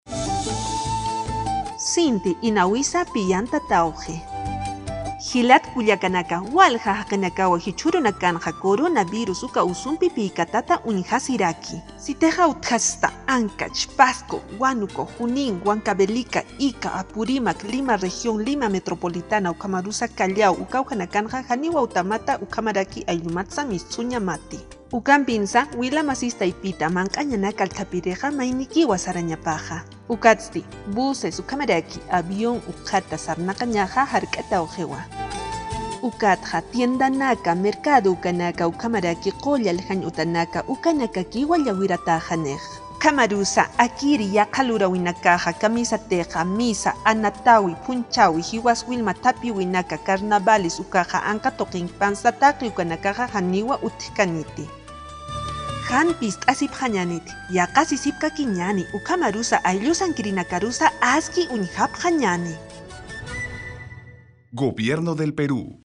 Spot de radio | Juntas y Juntos